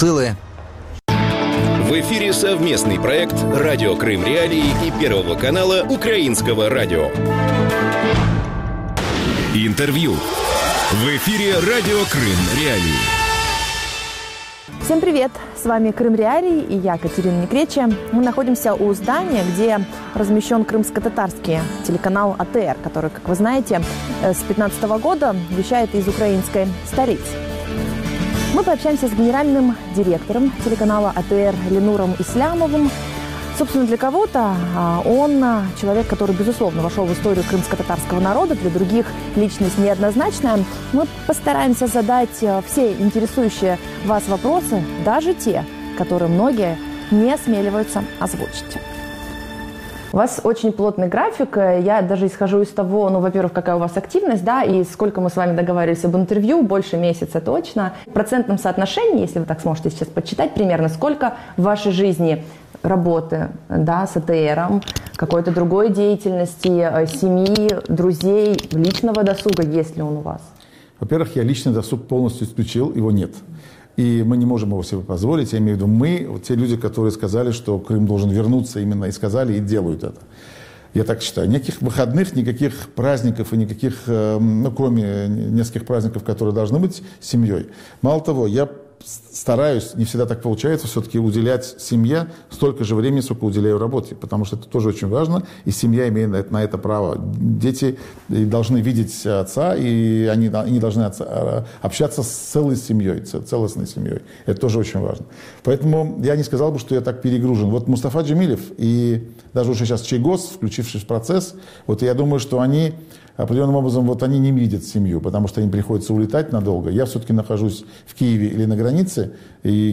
Политические амбиции и деоккупация Крыма. Интервью с Ленуром Ислямовым